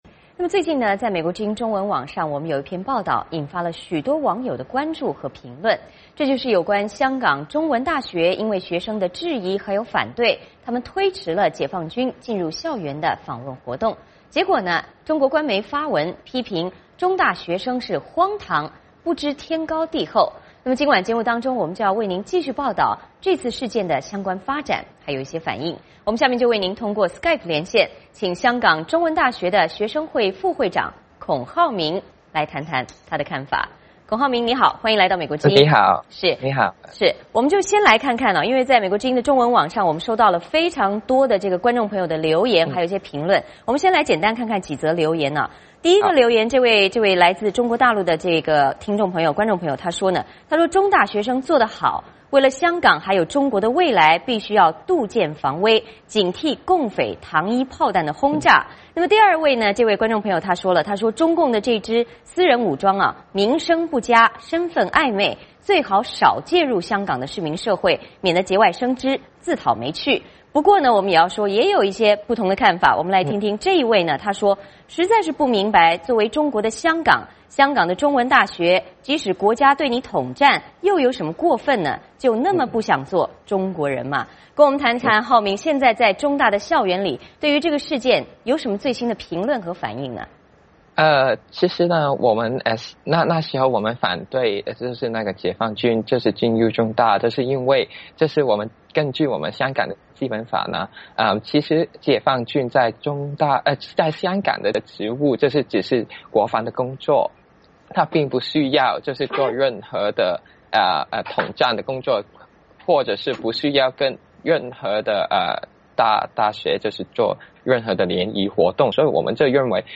最近美国之音中文网上一篇报道引发许多网友的关注和评论，这是有关香港中文大学因为学生的质疑和反对，推迟了解放军进入校园的访问活动，结果中国官媒发文批评中大学生是“荒唐，不知天高地厚”。今晚节目中我们为您继续报道事件的发展和反应。我们通过SKYPE连线